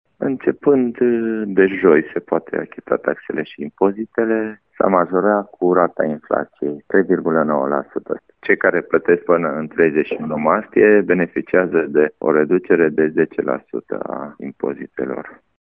Primarul municipiului Târnăveni, Sorin Megheșan: